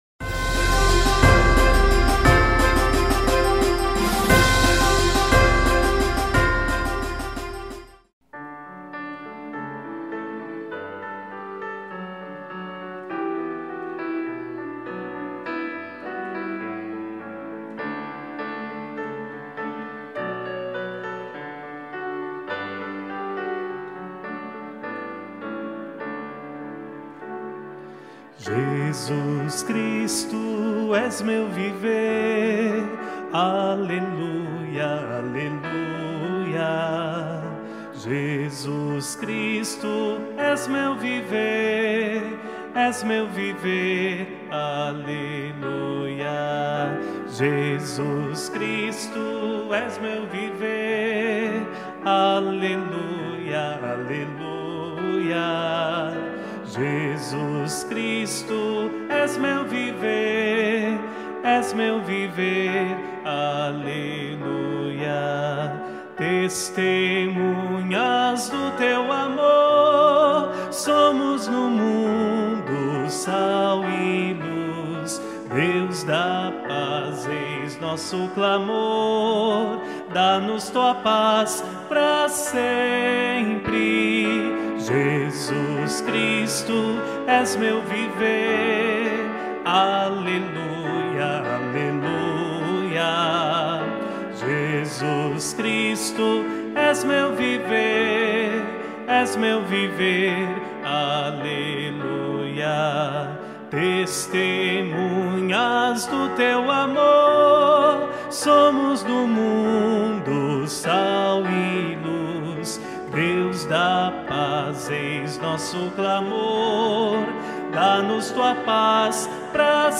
Música | Jesus Cristo, és meu viver
Festa da Padroeira 2015: Jesus Cristo, és meu viver
Letra/Música: Dom Marco Frisina